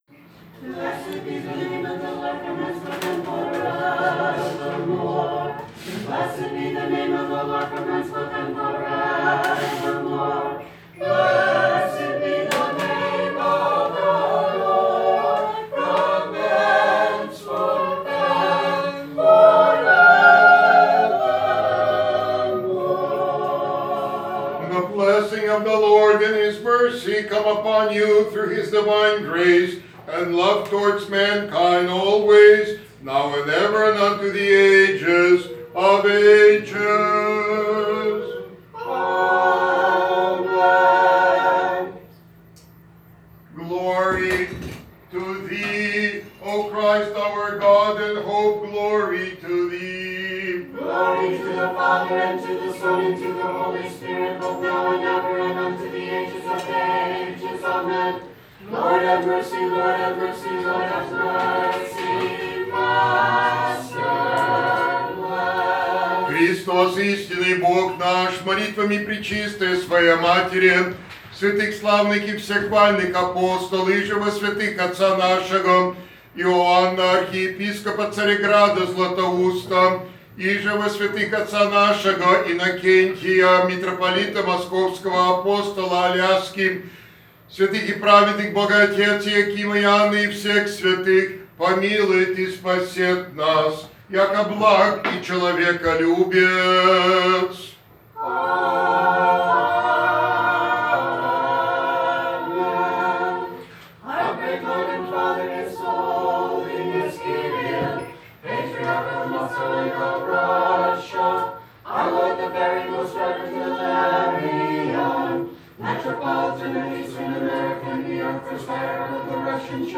Today, April 13, 2019, for our patronal feast day, Archbishop Peter came to visit his parish of Saint Innocent of Moscow.
Here is Archbishop Peter’s sermon for you to enjoy: